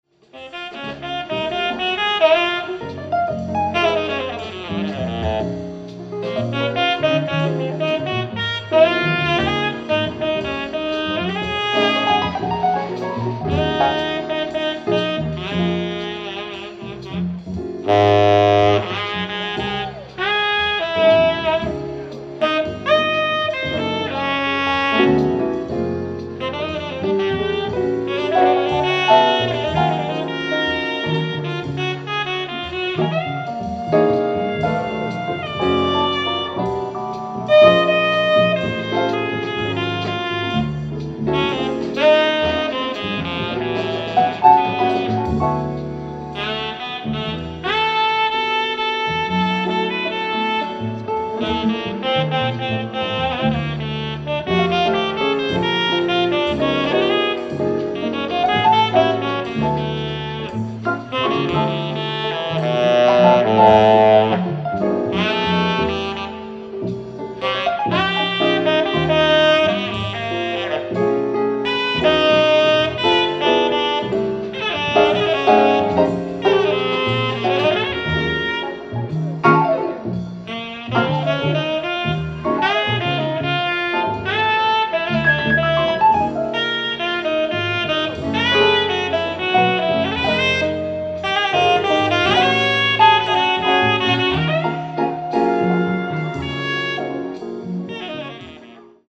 ライブ・アット・プレイボーイ・ジャズフェスティバル、ハリウッドボウル 06/16/1979